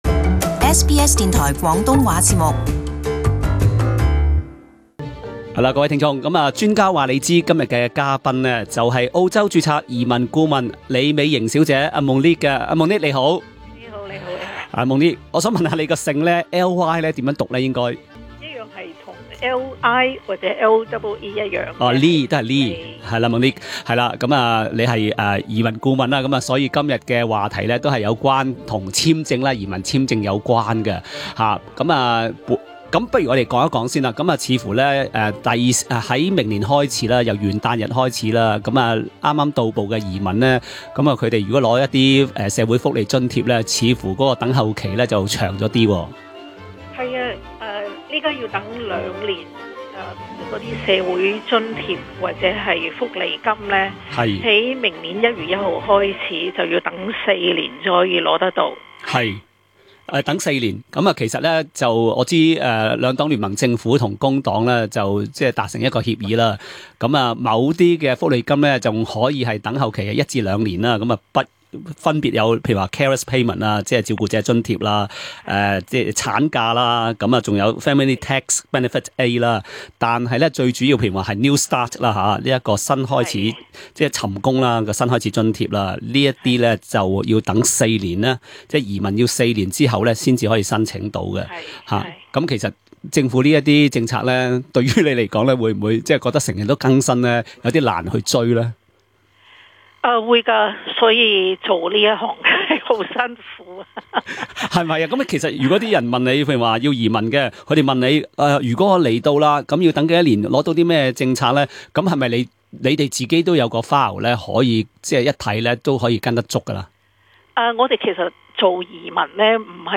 她還會回答聽衆致電提問，包括居民回頭簽證 (Resident Return visa 155)。